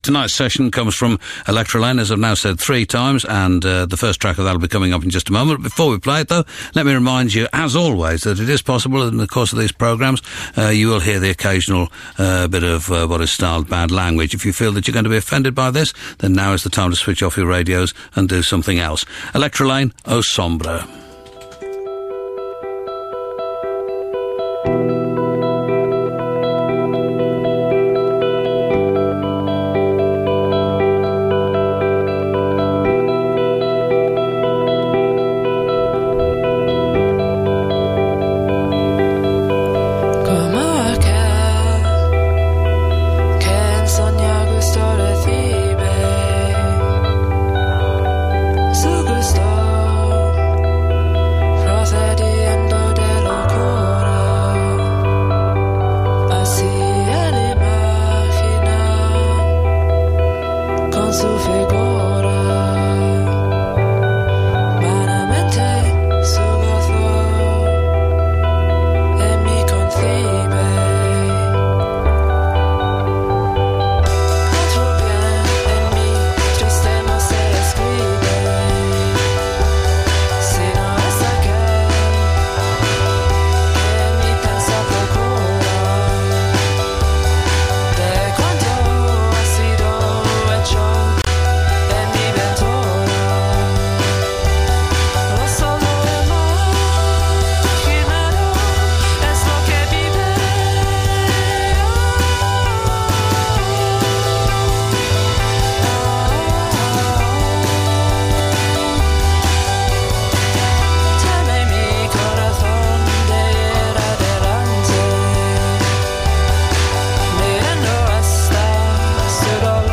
Feminist Post-Rock with an Experimental twist.